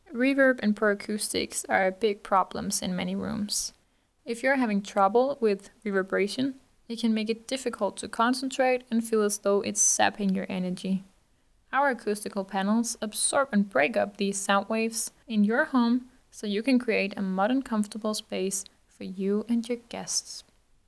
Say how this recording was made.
Great-acoustics-audio.wav